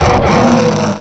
cry_not_zweilous.aif